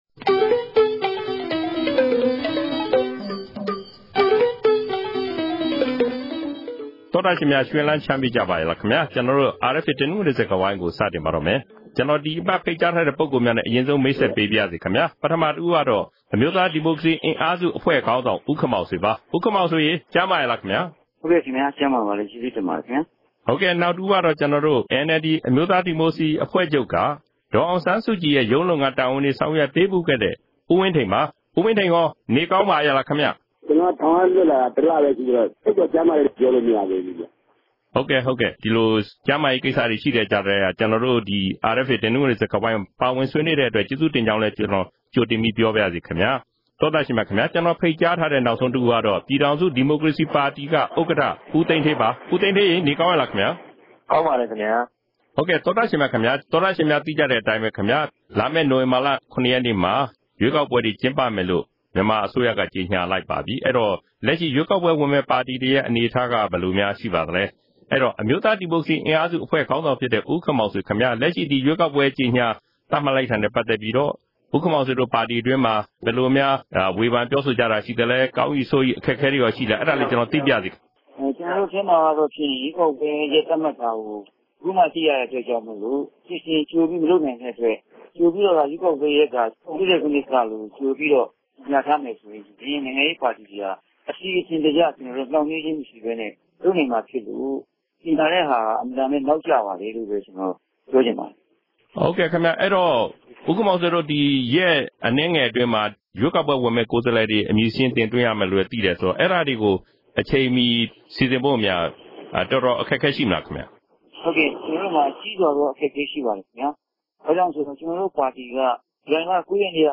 တနင်္ဂနွေစကားဝိုင်း အစီအစဉ်မှာ ၂ဝ၁ဝ ရွေးကောက်ပွဲနဲ့ တင်းကျပ်တဲ့ စည်းမျဉ်းစည်းကမ်းတွေ၊ လုပ်ထုံးလုပ်နည်းတွေနဲ့ ပတ်သက်ပြီး ဆွေးနွေးထားကြပါတယ်။